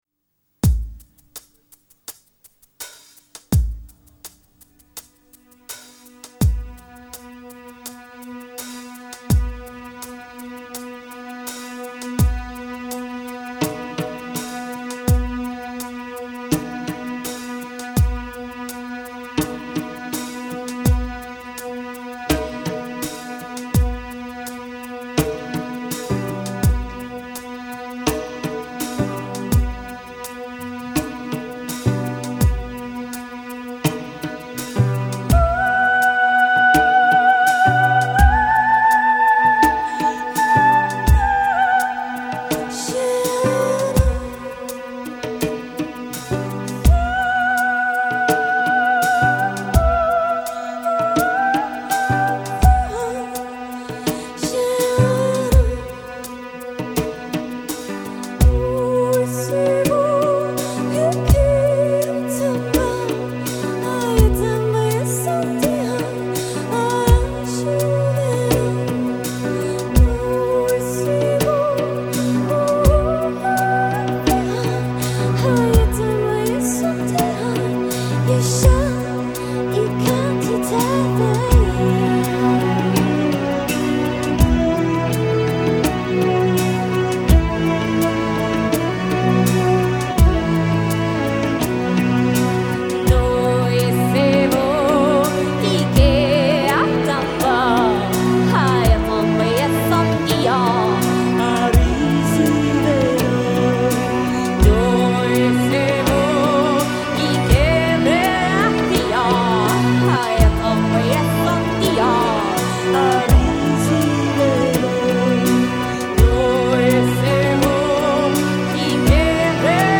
Wat kan dat mens zingen zeg!!
Whoa, idd, stemmetje!